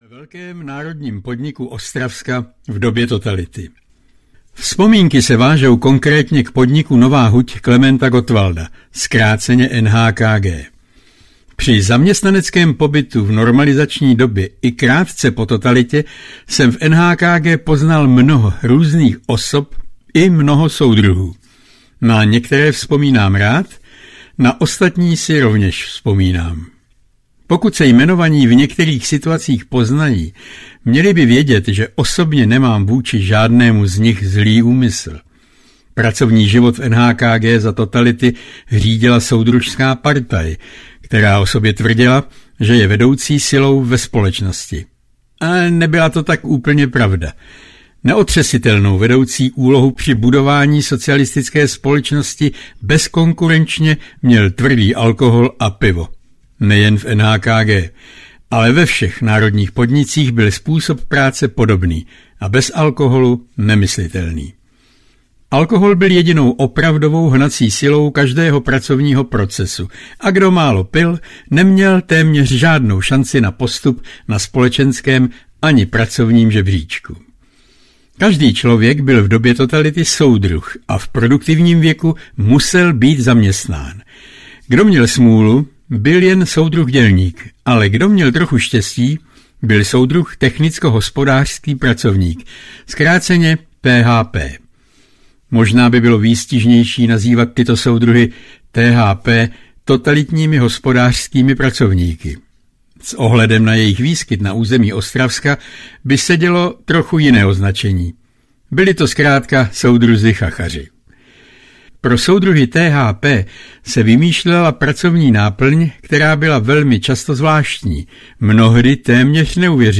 Soudruzi chachaři audiokniha
Ukázka z knihy